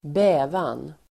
Ladda ner uttalet
bävan substantiv, dread Uttal: [²b'ä:van]